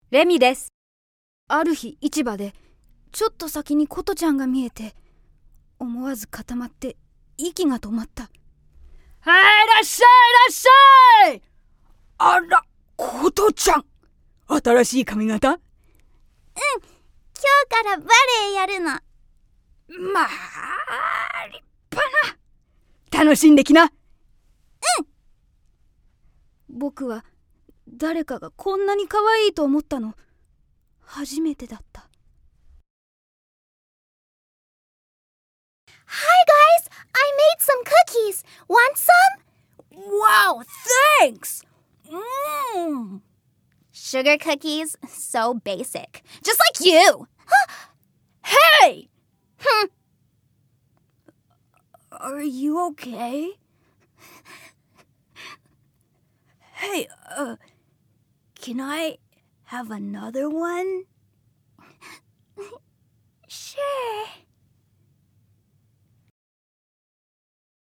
◆台詞